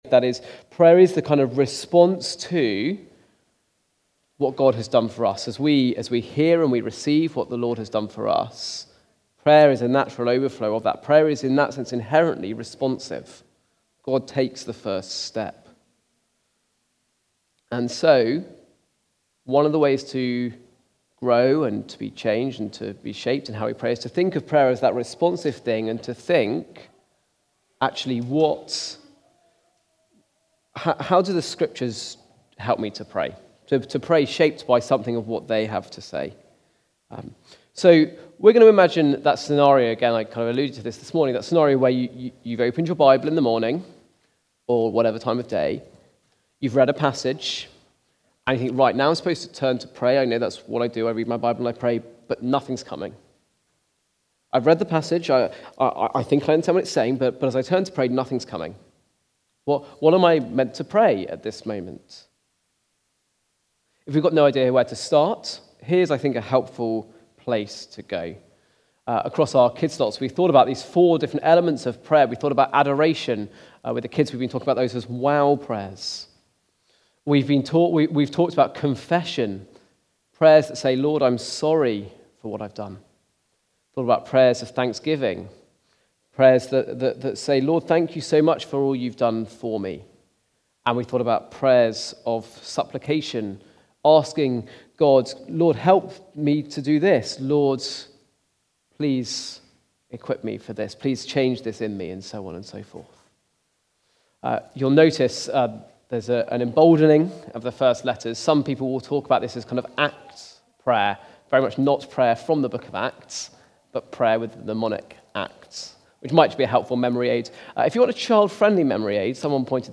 Using the ACTS model to pray (Luke 22:1.23) Recorded at Woodstock Road Baptist Church on 16 February 2025.